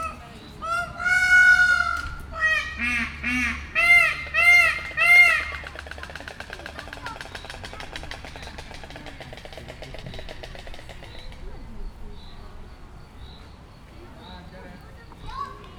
pava_fehergolya00.15.WAV